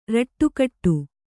♪ raṭṭu kaṭṭu